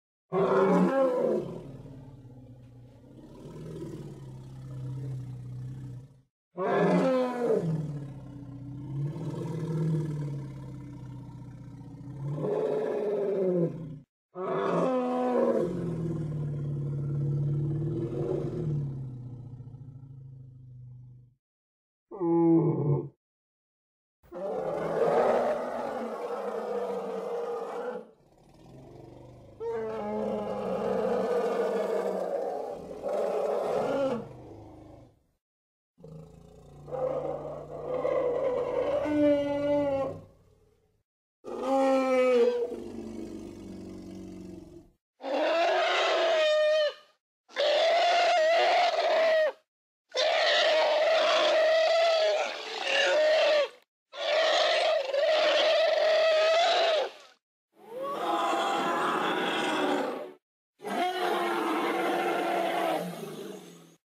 Звук, когда мамонту плохо, он болеет или ему больно